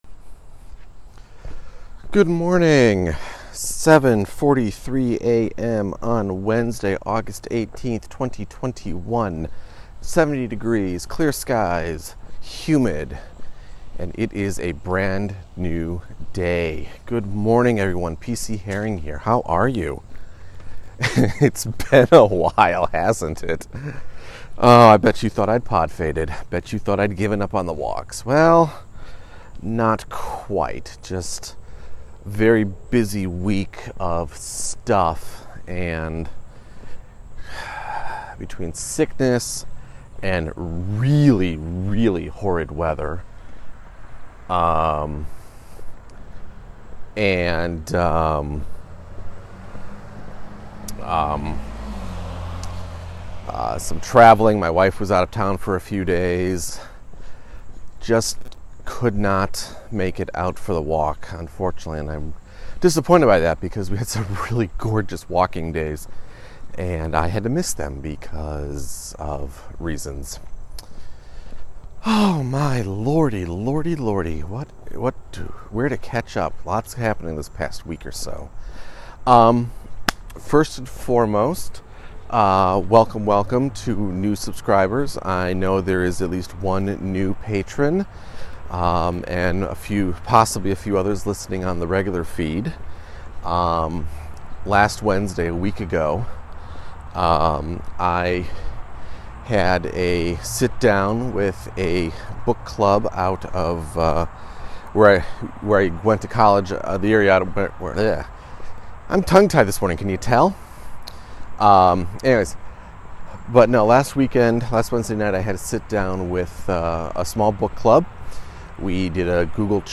After a much longer than desired break, I return to the walk and talk. There are more than a few pieces of news, I welcome new listeners, and a new Patron and I talk about getting on with the next wave of new habits.